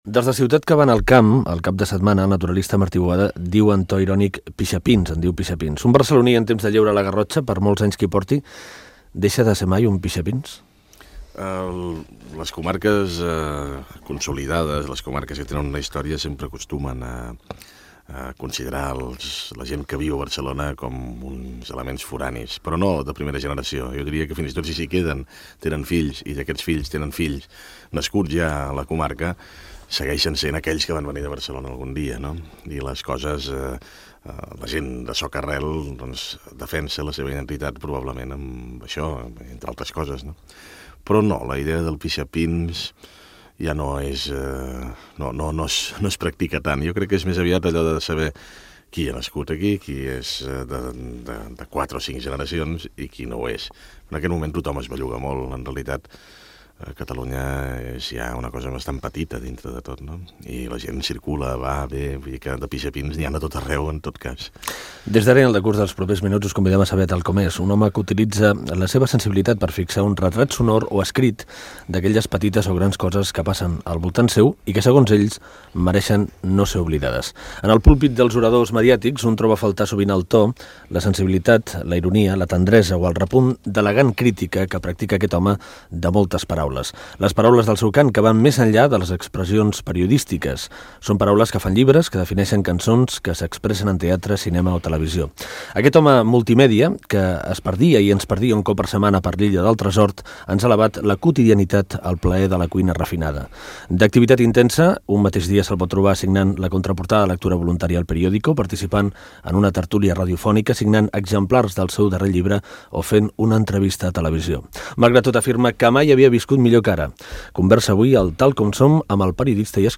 Fragment d'una entrevista al periodista Joan Barril.
Entreteniment